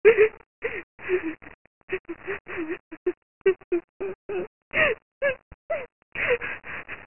crying.mp3